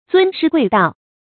尊師貴道 注音： ㄗㄨㄣ ㄕㄧ ㄍㄨㄟˋ ㄉㄠˋ 讀音讀法： 意思解釋： 亦作「尊師重道」。